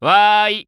Mike's voice from the official Japanese site for WarioWare: Move It!
WWMI_JP_Site_Mike_Voice.wav